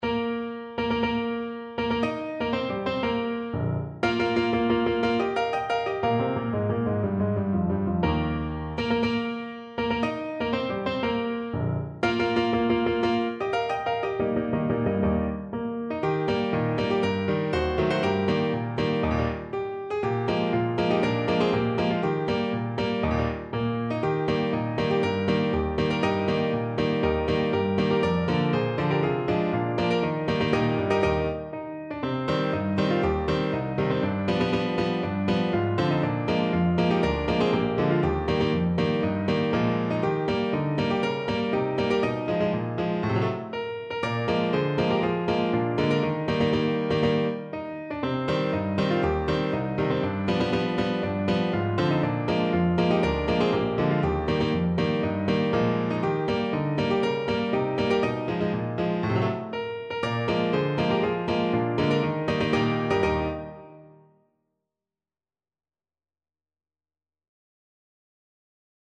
2/4 (View more 2/4 Music)
Bb4-Eb6
Allegro (View more music marked Allegro)